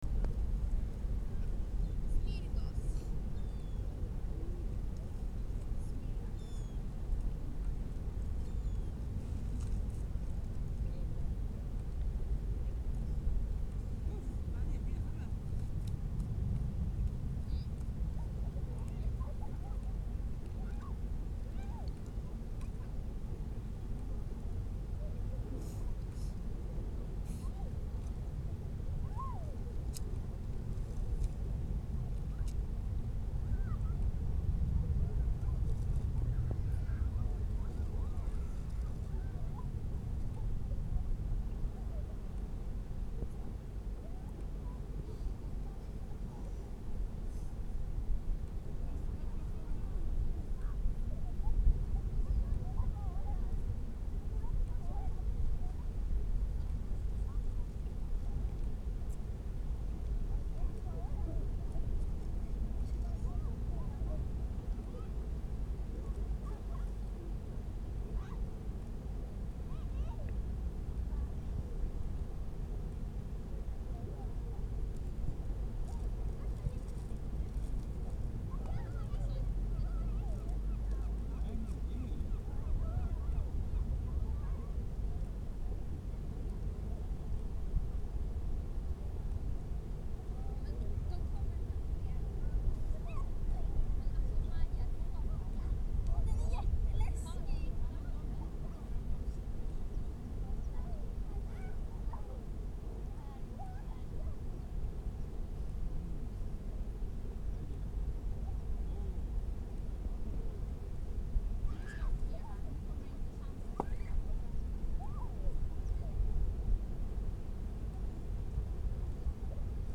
Falkenberg Sweden Beach